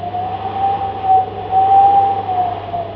tornado.wav